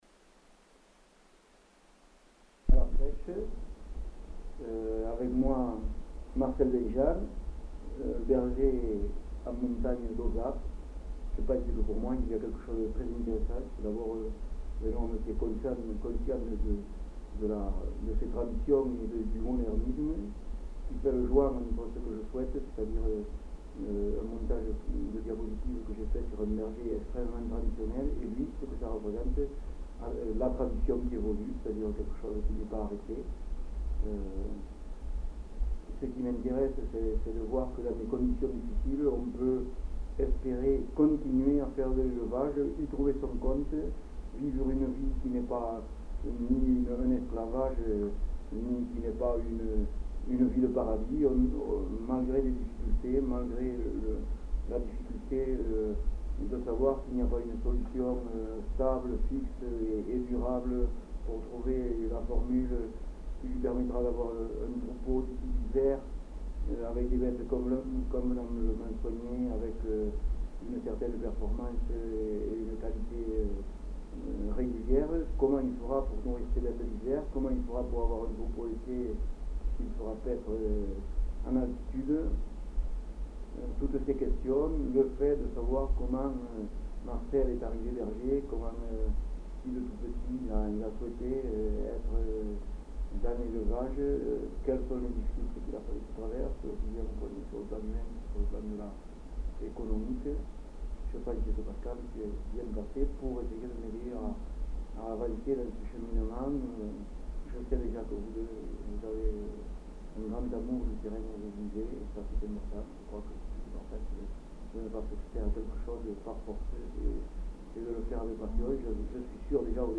924 Nature du document : enquête Nombre de supports
Durée/Pagination : 40min 19s Type de son : stéréo Qualité technique : très mauvais Résumé : L'enregistrement est réalisé auprès d'un jeune berger travaillant essentiellement en montagne et, plus précisément, en montagne d'Ossat. Une jeune femme qui le connaît assiste le collecteur en posant des questions ou en apportant des précisions. Le document contient des informations sur le métier de berger, l'élevage des brebis, le travail en montagne.